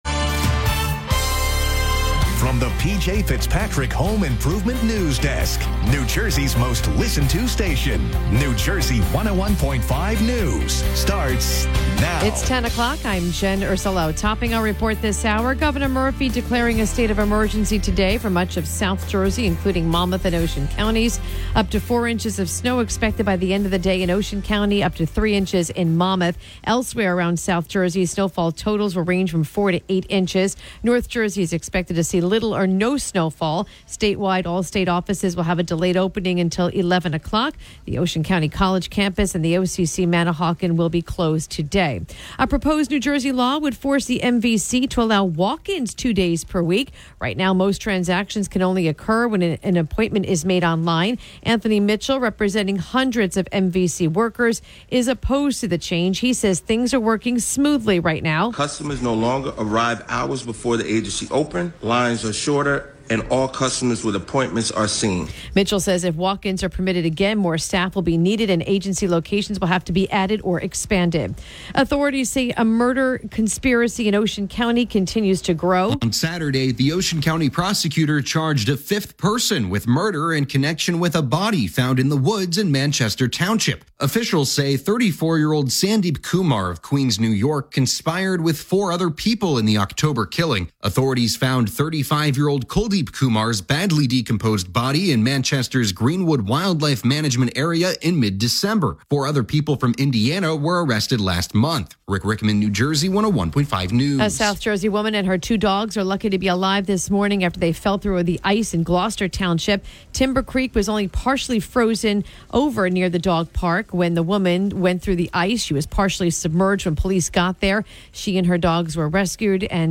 The latest New Jersey news and weather from New Jersey 101.5 FM, updated every hour, Monday through Friday.